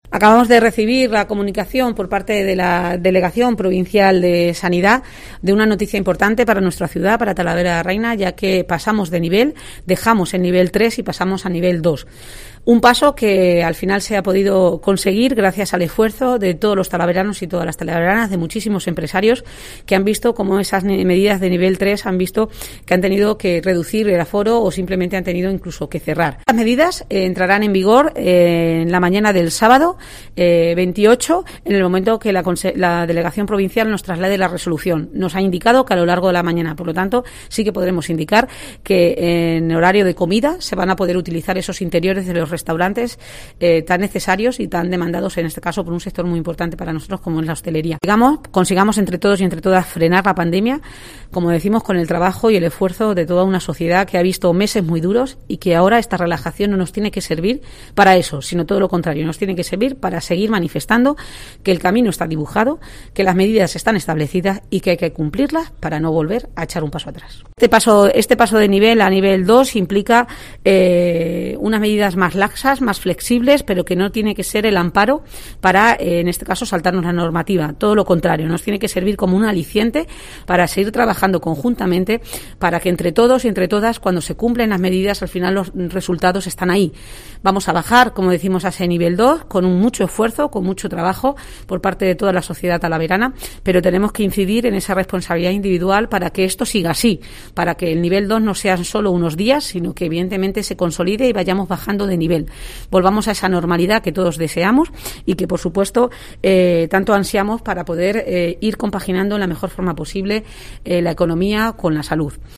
Declaraciones de Tita García Élez